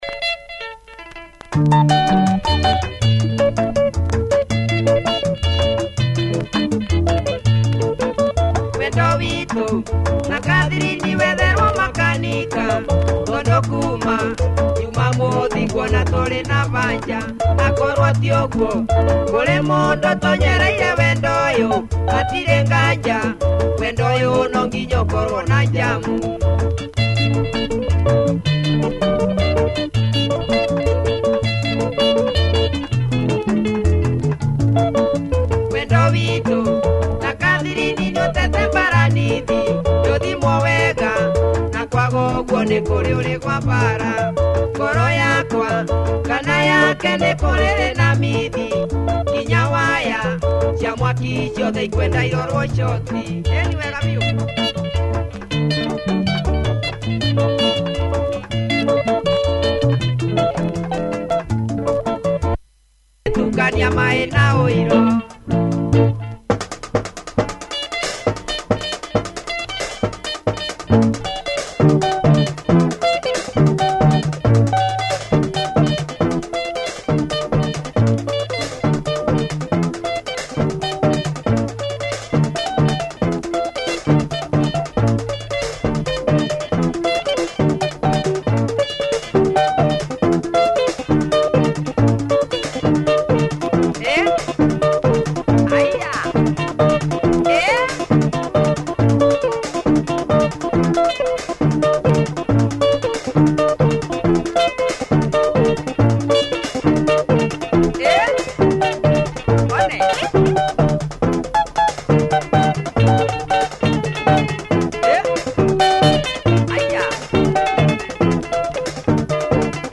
Benga
solid breakdown
nice harmonica